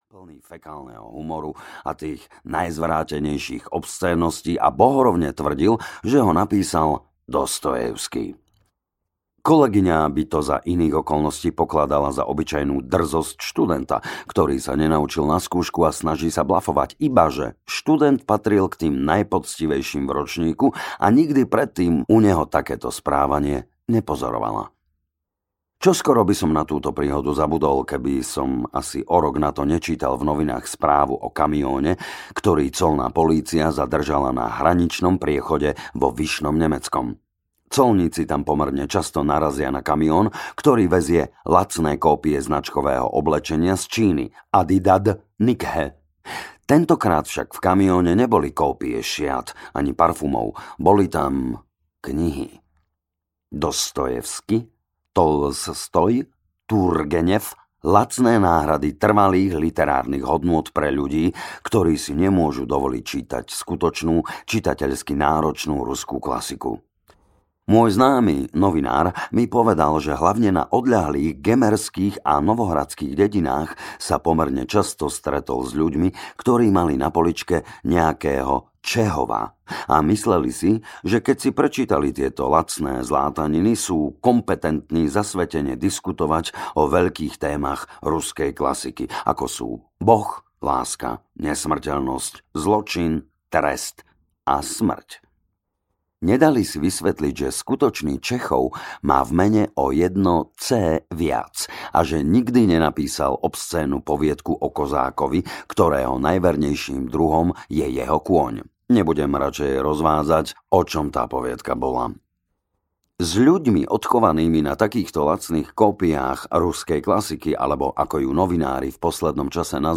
Ruzká klazika audiokniha
Ukázka z knihy